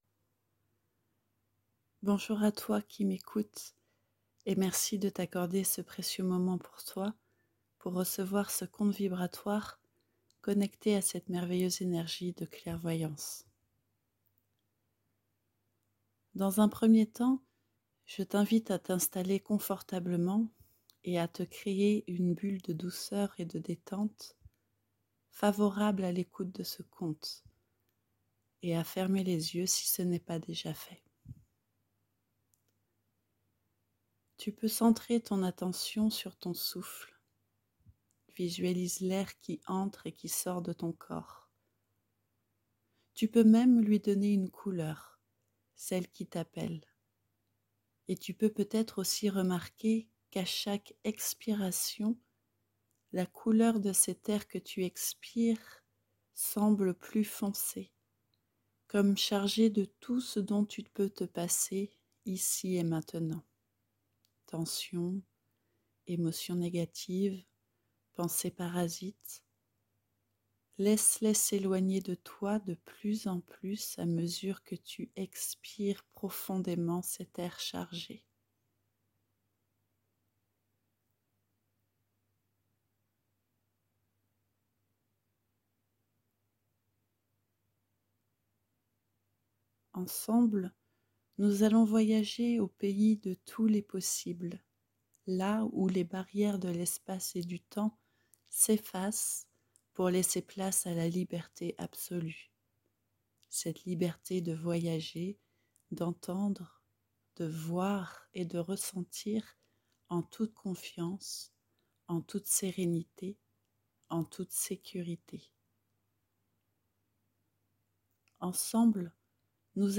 Si toi aussi tu es en quête de clairvoyance, télécharge le conte vibratoire que je te partage sous forme de visualisation guidée!